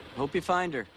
【ほぅぴゅふぁぃんだ】
• 「I」はほぼ聞こえない
• 「hope you」は「ほぅぴゅ」と繋がる
• 「find her」は「ふぁいんだ」と音が変化